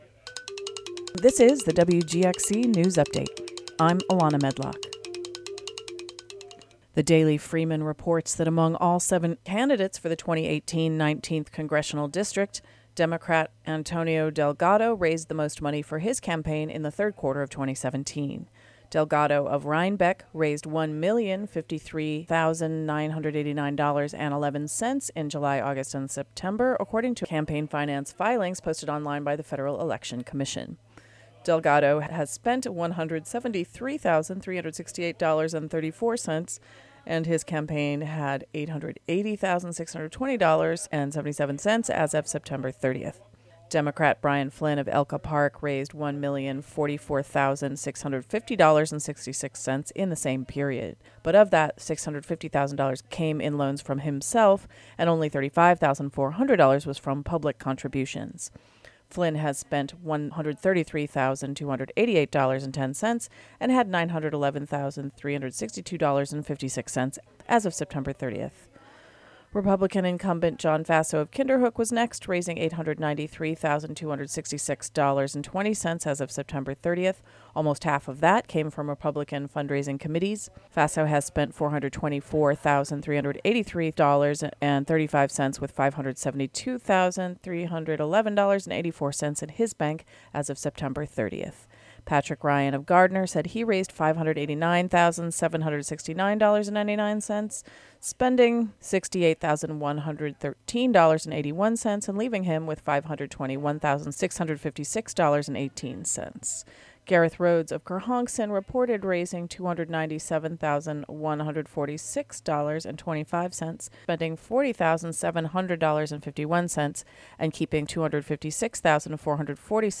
WGXC Local News Update Audio Link